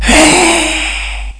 1 channel
FScream1.mp3